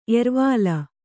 ♪ ervāla